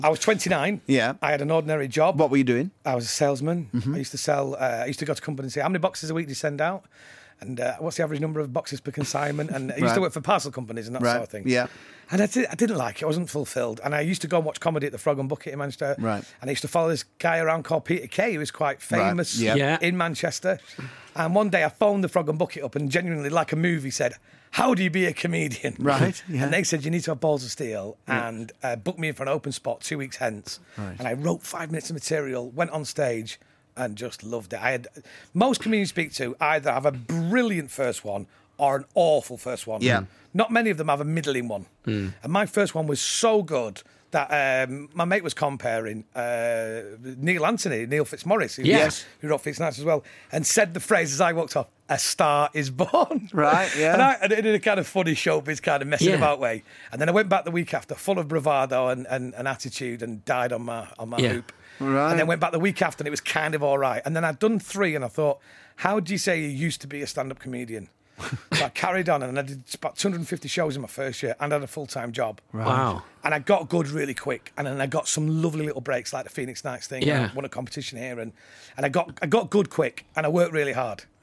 Justin Moorhouse chats to Mark Radcliffe and Stuart Maconie about how he became a comedian.